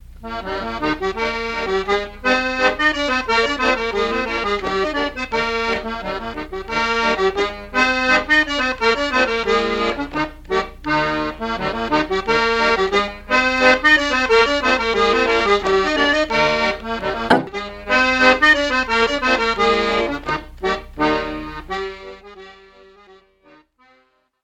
acordeon.mp3